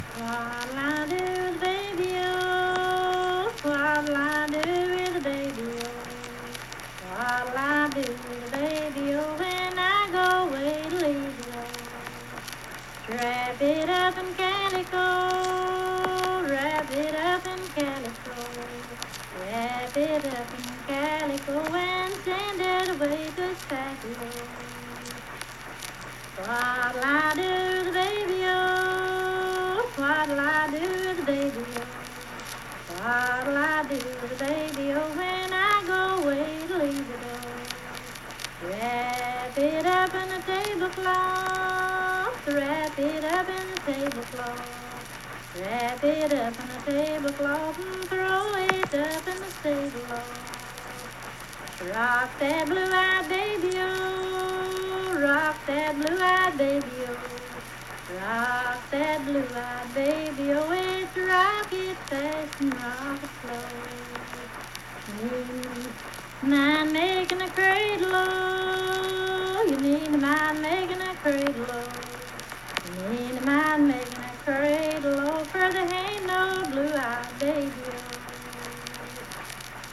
Unaccompanied vocal music
Verse-refrain 6(4w/R).
Children's Songs
Voice (sung)
Harts (W. Va.), Lincoln County (W. Va.)